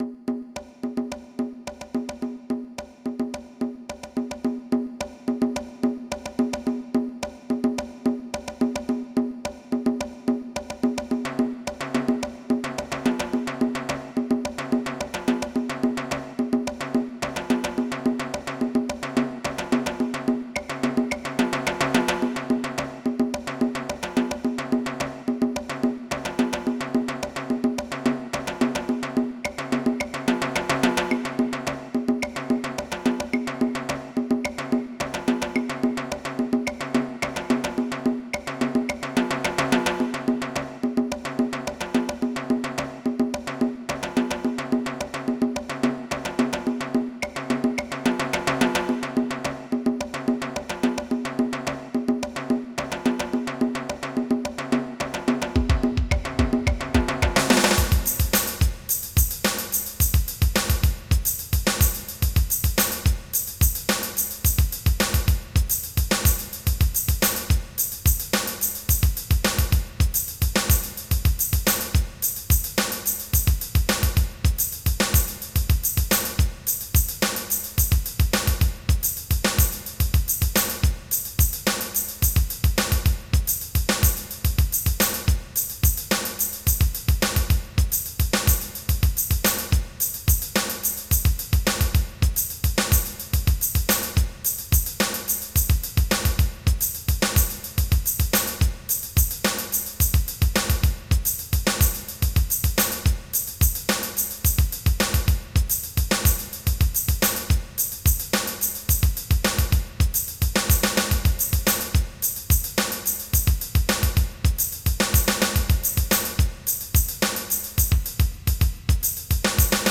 MIDI Music File
DRUMTRK1.mp3